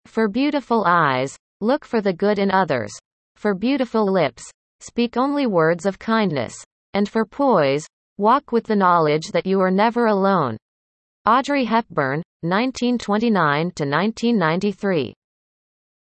(Text-to-Speech by Sound of Text, using the engine from Google Translate)